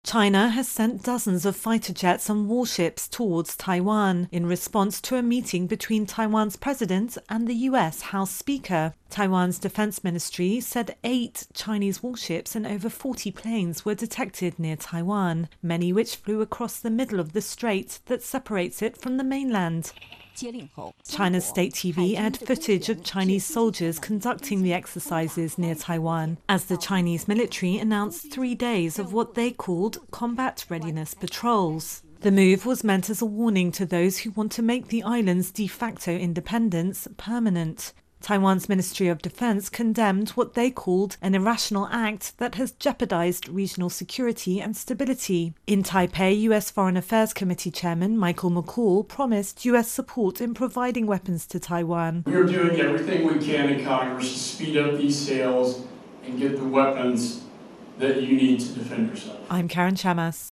reports on China US Taiwan